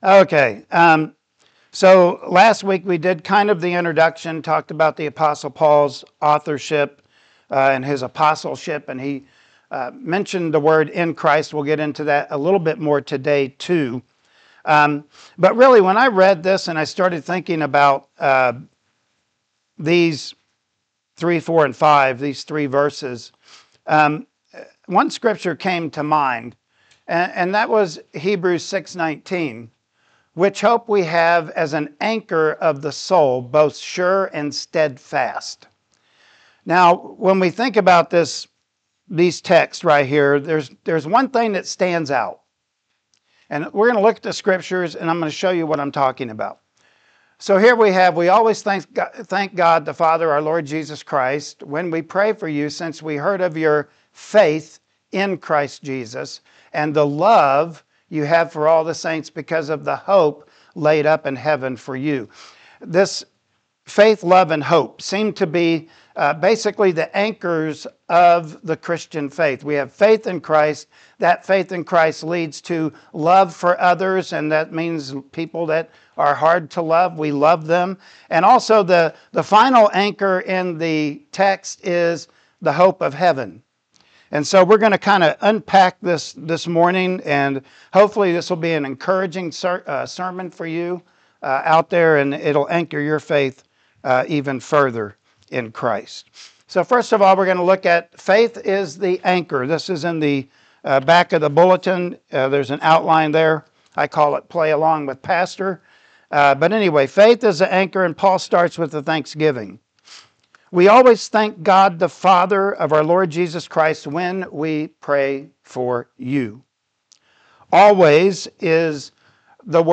Colossians 1:3-5 Service Type: Sunday Morning Worship Service Topics: Faith , Love & Hope